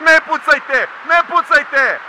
This will make the actors sound like they are in the open, or inside a button-down vehicle, or in a plane speaking through a radio. For instance, some of the Chetnik lines above after the audio treatment:
Serb_Chetnik_Panicking_ne_pucajte_ne_pucajte